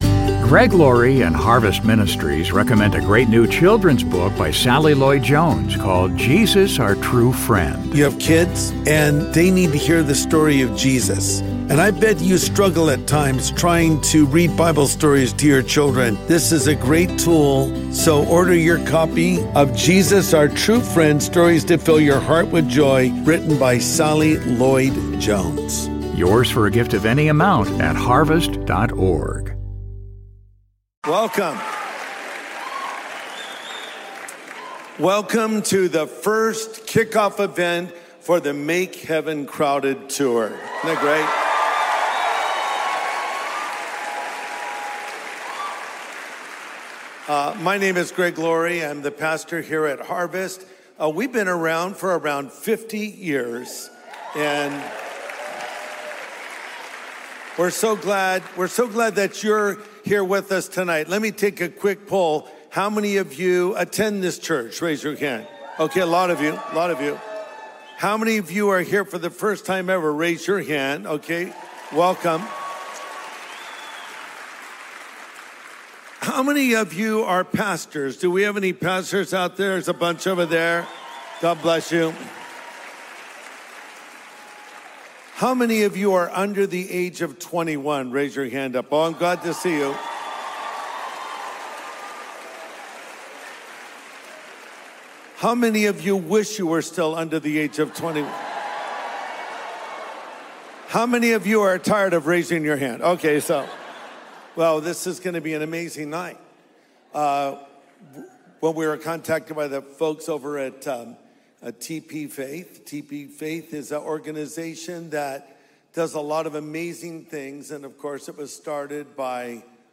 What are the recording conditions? My Full Speech at TPUSA Faith's Make Heaven Crowded Tour